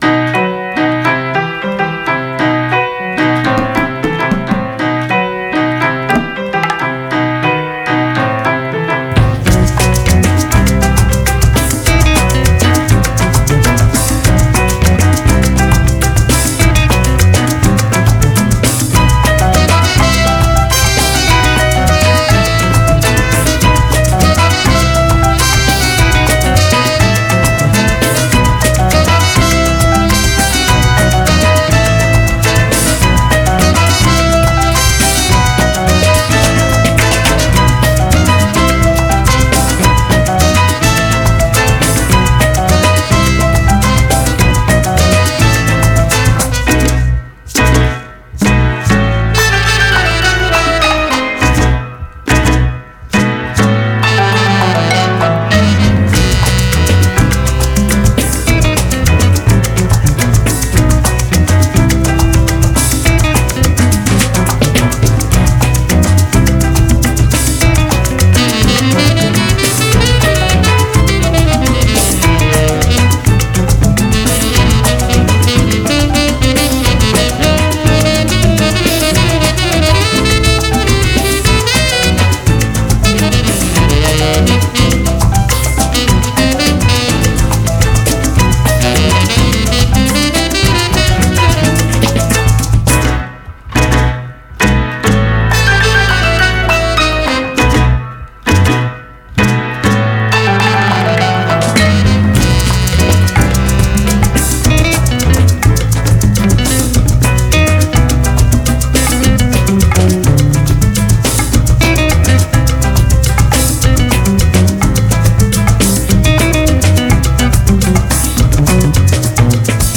Жанр: soul / funk / latino Битрейт